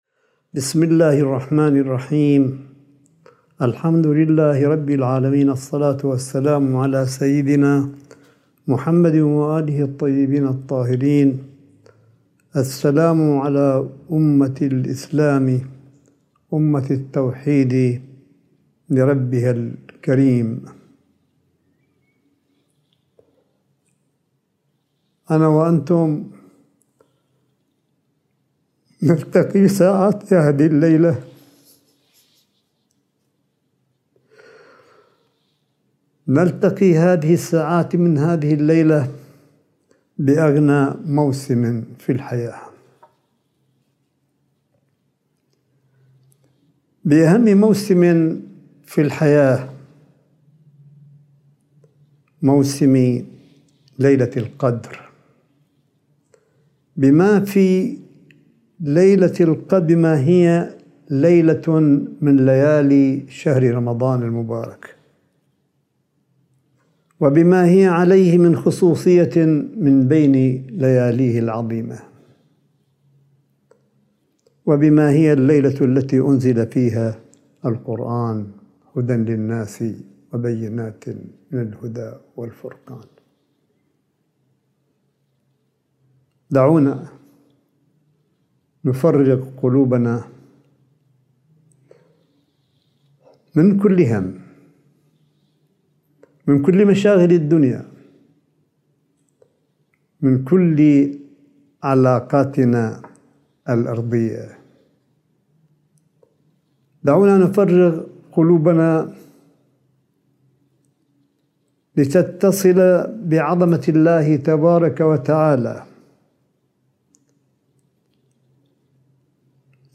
صوت : الكلمة الروحية لسماحة آية الله قاسم في ليلة القدر المباركة ١٤٤٢هـ – ٢٠٢١م
ملف صوتي للكلمة “المختصرة” لسماحة آية الله الشيخ عيسى أحمد قاسم في ليلة القدر المباركة ١٤٤٢هـ – ٥ مايو ٢٠٢١